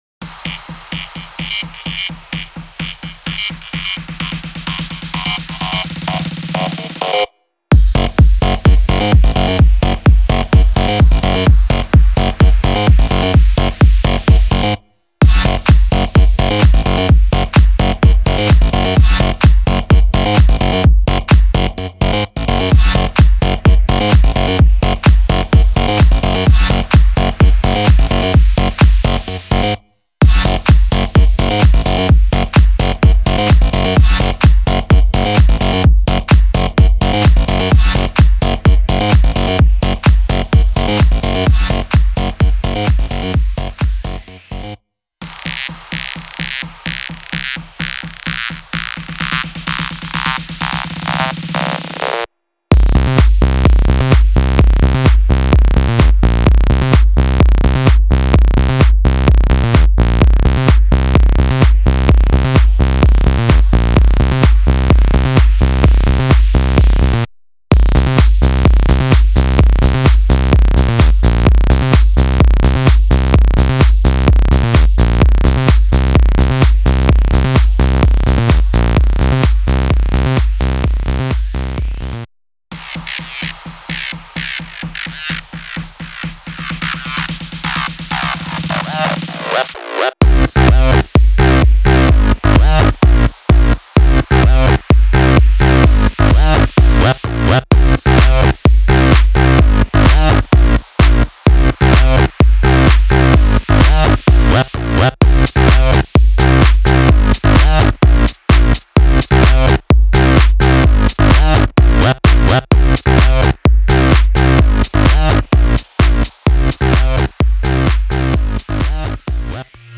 - 5 EDM Construction Kits
- 26 One shots drums and FX
- 5 Bass MIDI loops